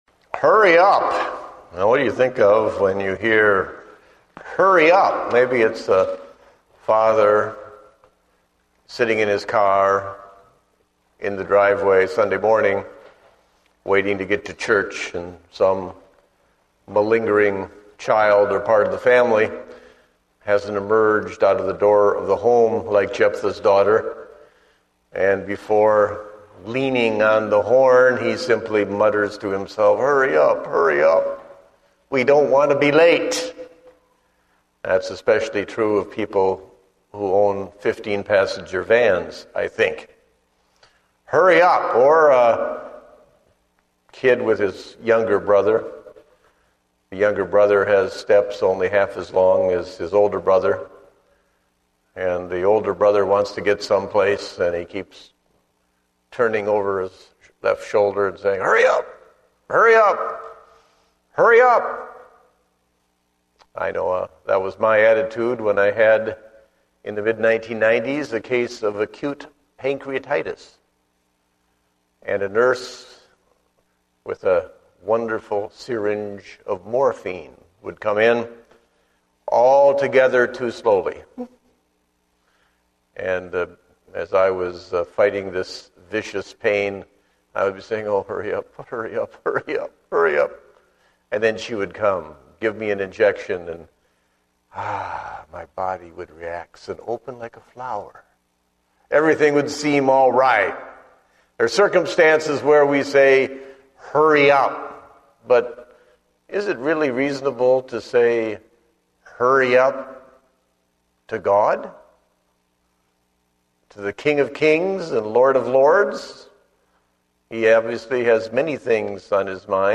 Date: August 8, 2010 (Evening Service)